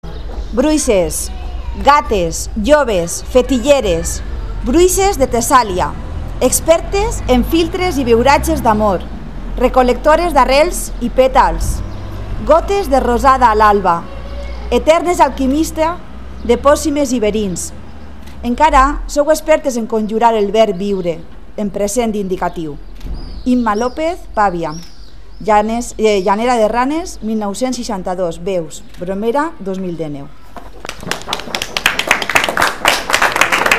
Tras el tiempo de silencio, la concentración ha concluido con la lectura del poema “bruixes”